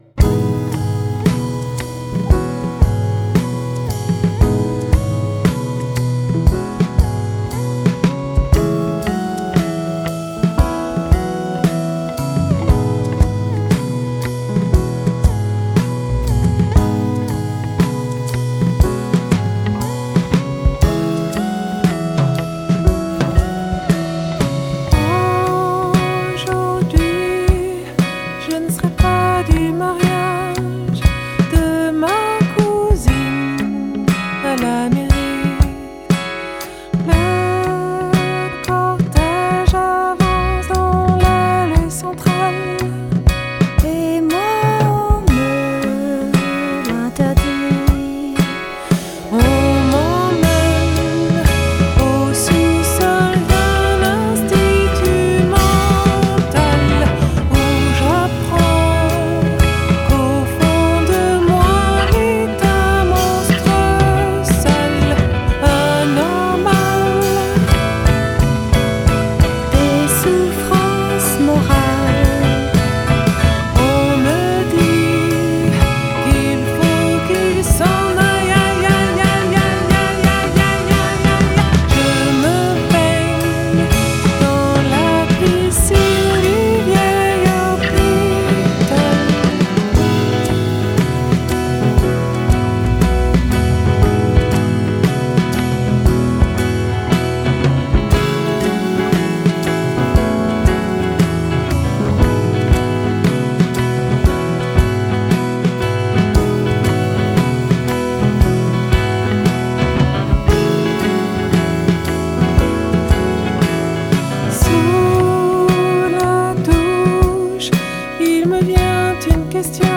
warmth and melody
It’s a pretty, swaying piece, sung in French